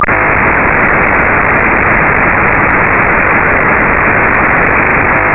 Sample of P25 Phase I in C4FMContinuous 4-Level Frequency Modulation from a Motorola XTS5000. Unfiltered (discriminator) audio.
P25-C4FM-VC_AF.wav